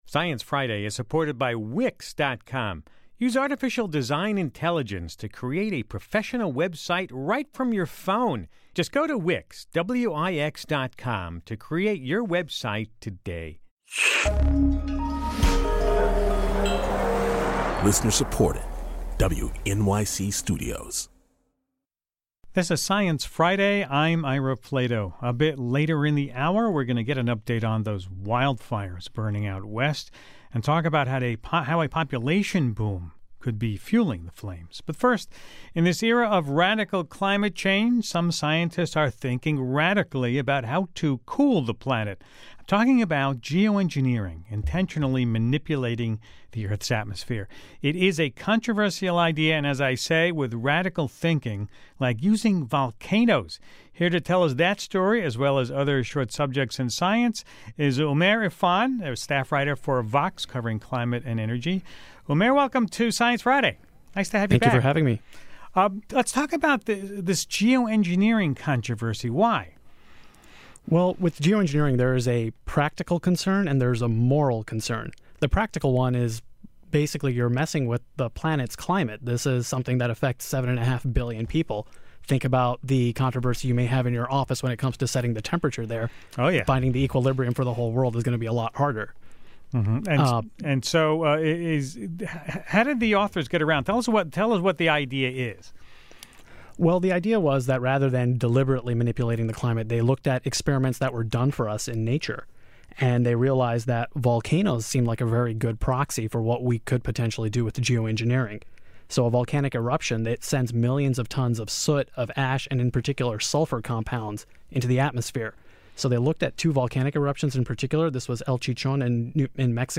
He (and his guitar) join Ira to discuss his findings.